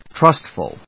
/trˈʌstf(ə)l(米国英語)/